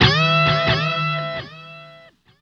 Index of /90_sSampleCDs/Zero-G - Total Drum Bass/Instruments - 2/track39 (Guitars)
07 Vai-able E.wav